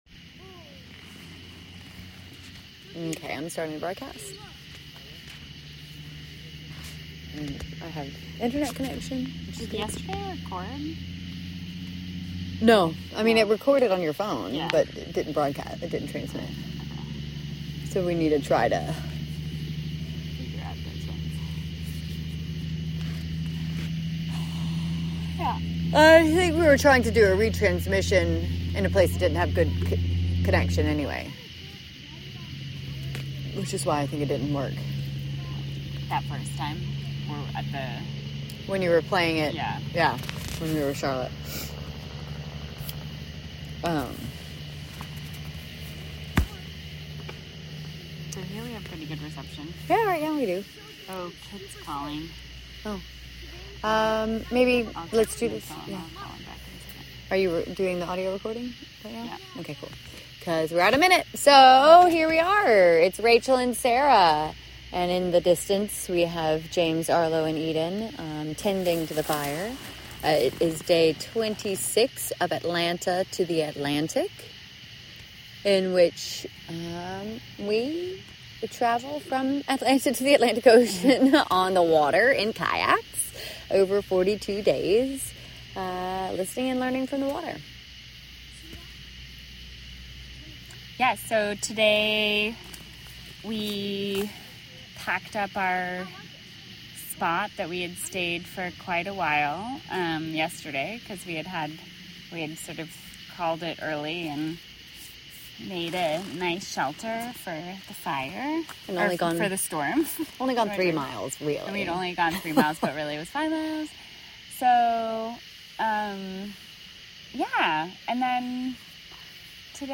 report from a sandbar around mile 46 on the Ocmulgee River